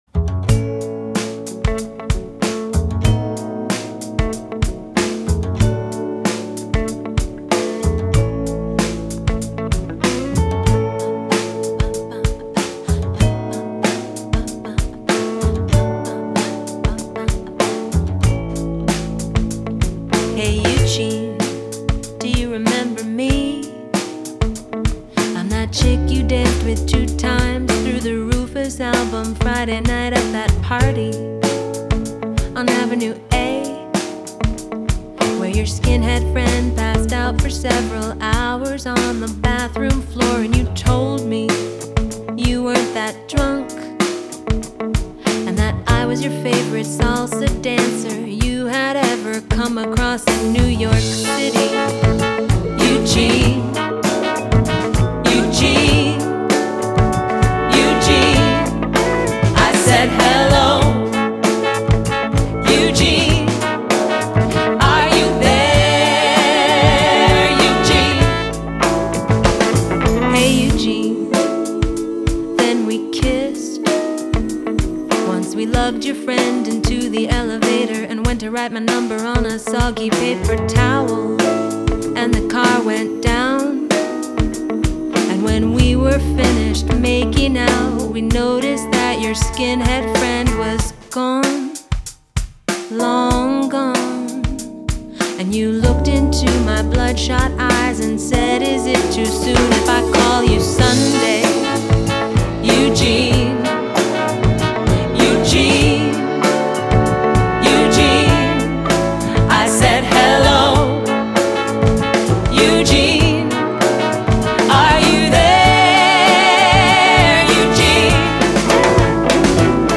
Genre: Jazz,Latin